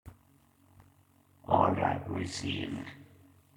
Now THAT sounds cool!
ok but remember i only do cabal unit sounds/voices while my normal voice didn't fit any type of human adult soldier that good.